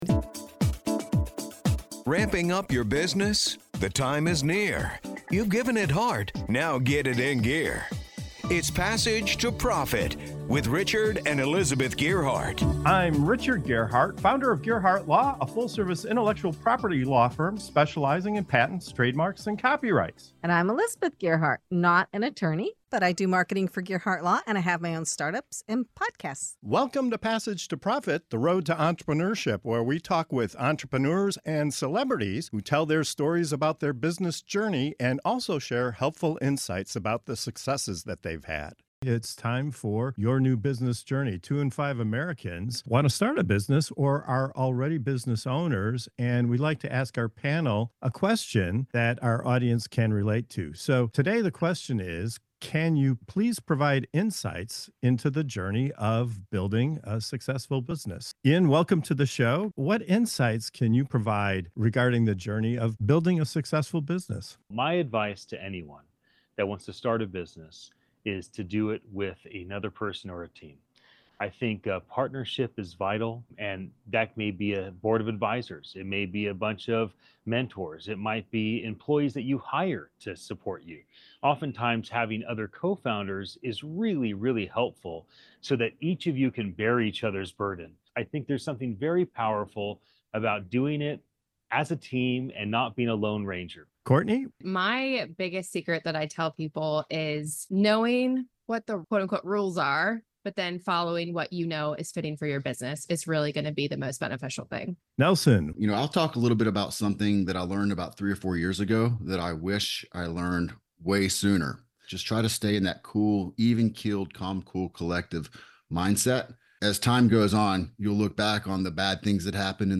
Whether you're a seasoned entrepreneur, a startup, an inventor, an innovator, a small business or just starting your entrepreneurial journey, tune into Passage to Profit Show for compelling discussions, real-life examples, and expert advice on entrepreneurship, intellectual property, trademarks and more.